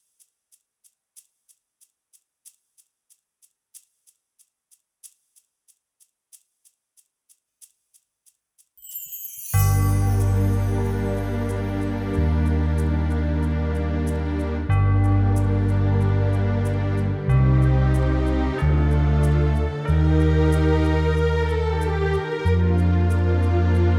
Minus Piano Pop (1970s) 4:19 Buy £1.50